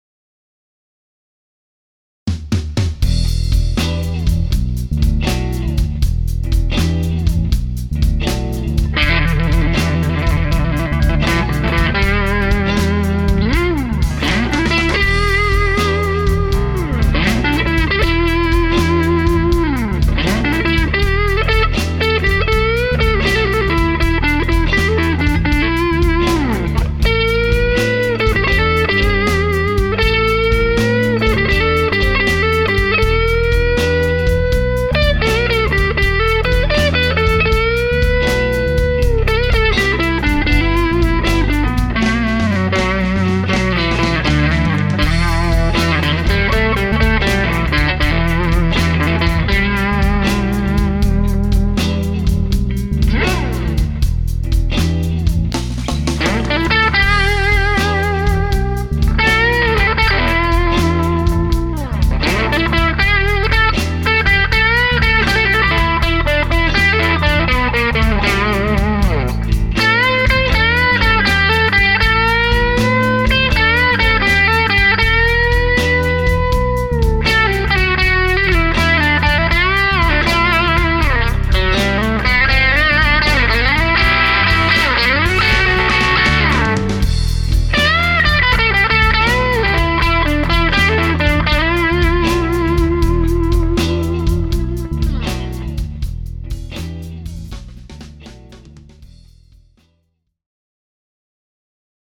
The only exception to that is with the last clip I recorded where I ran my Strat through my Timmy overdrive before going into the amp, which was not quite at the breakup level; just slightly below.
Clip from an SRV tribute song I wrote called “In The Vibe”
I used a single mic – a Sennheiser e609 – positioned about 18″ from the cabinet pointed directly at the center of the cone. Part of why you might hear a little static is the ambient room noise from my garage.